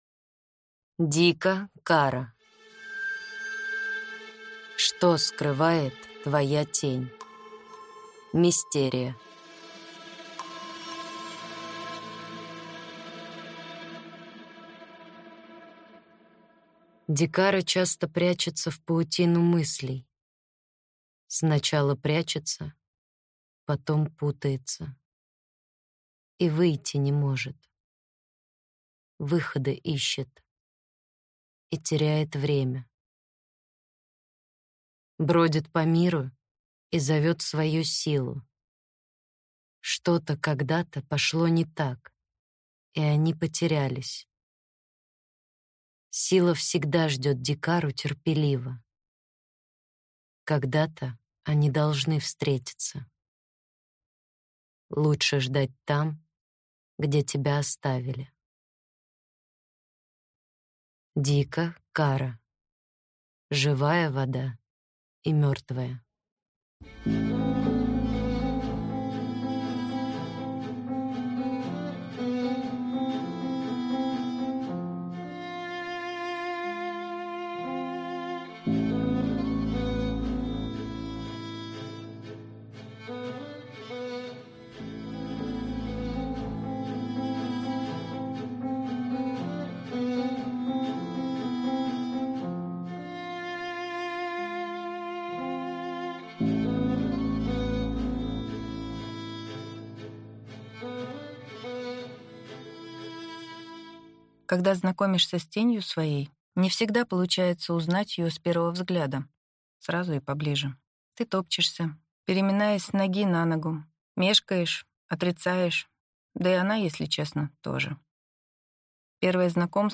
Аудиокнига Что скрывает твоя тень?